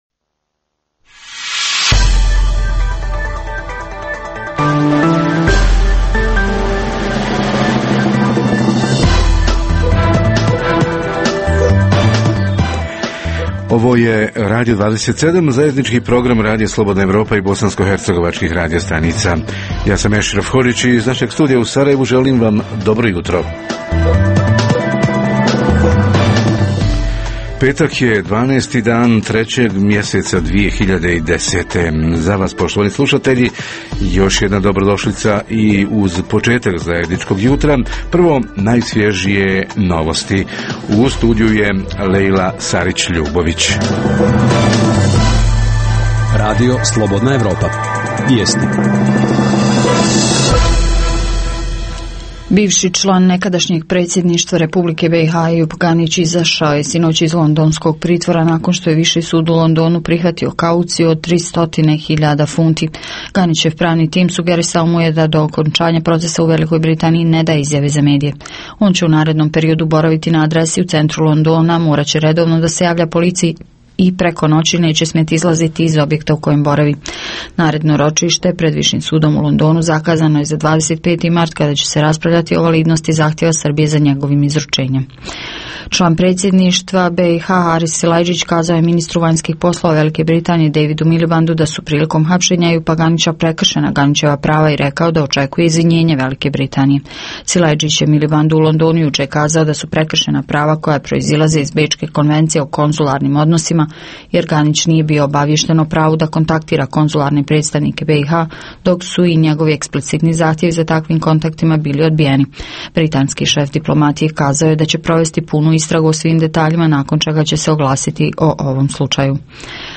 Nevladine organizacije – troše li namjenski dodjeljena donatorska sredstva i šta su pokazali posljednji revizorski pregledi? Reporteri iz cijele BiH javljaju o najaktuelnijim događajima u njihovim sredinama.
Redovni sadržaji jutarnjeg programa za BiH su i vijesti i muzika.